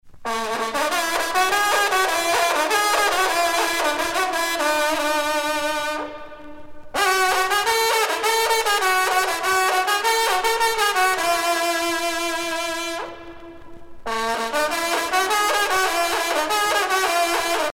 trompe - fanfare - lieux-dits
circonstance : vénerie
Pièce musicale éditée